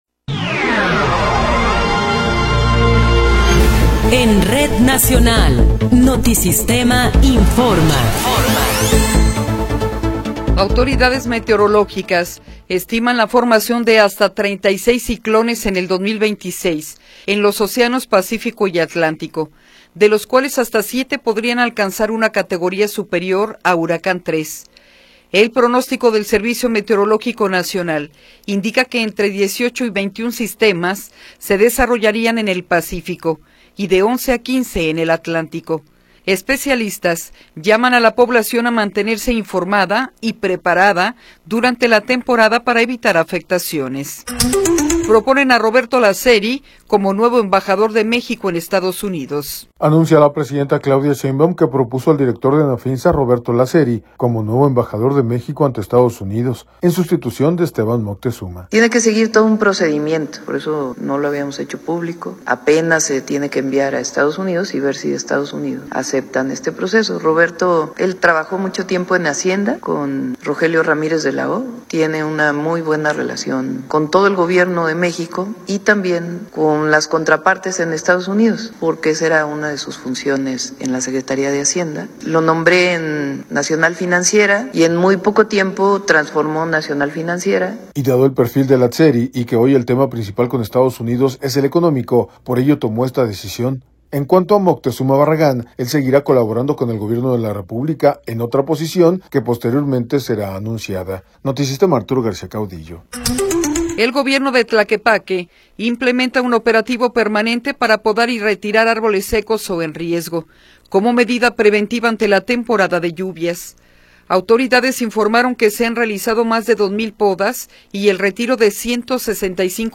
Noticiero 10 hrs. – 23 de Abril de 2026